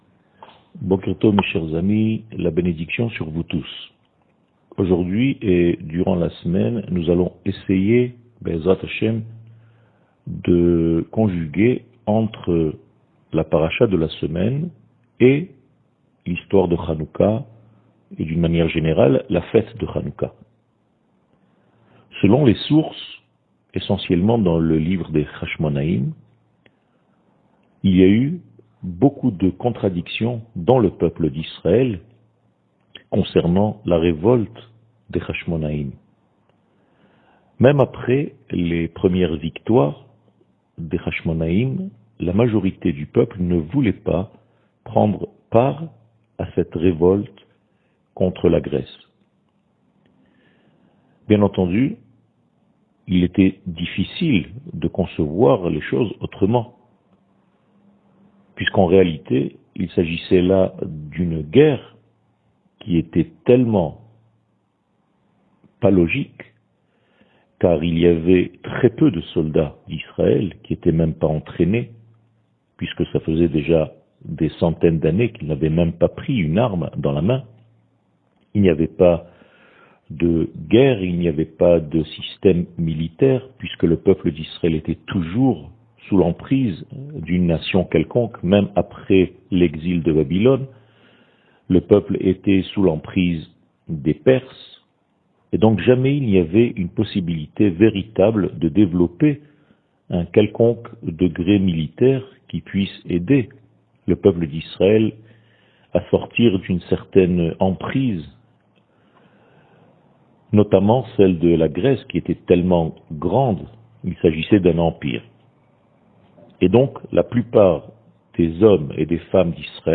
שיעור מ 28 נובמבר 2021